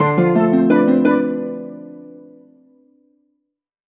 collectable_item_bonus_02.wav